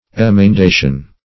Emendation \Em`en*da"tion\, n. [L. emendatio: cf. F.